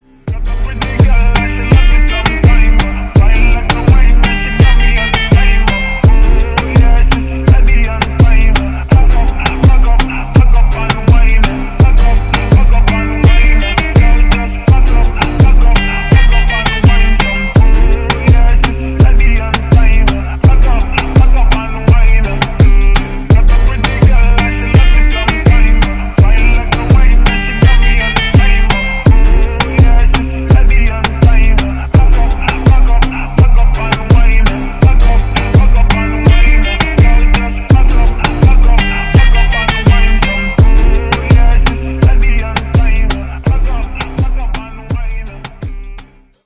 • Качество: 320, Stereo
восточные мотивы
зажигательные
заводные
пианино